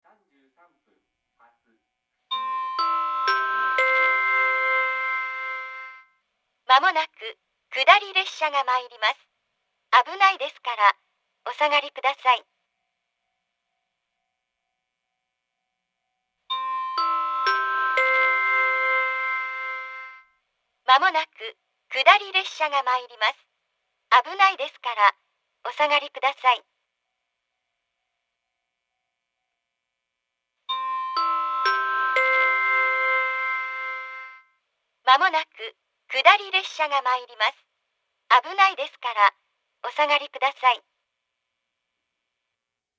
１番線接近放送
sansai-1bannsenn-sekkinn.mp3